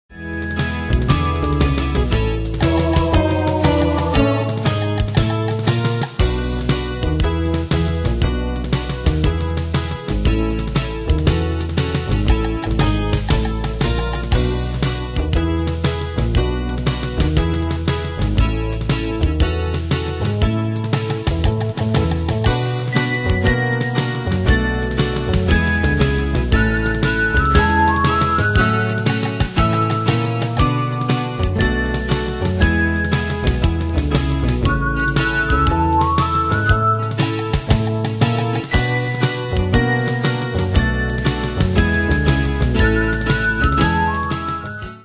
Demos Midi Audio
Style: Retro Année/Year: 1968 Tempo: 119 Durée/Time: 3.12
Danse/Dance: Pop Cat Id.
Pro Backing Tracks